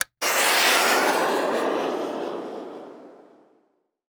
fire2.wav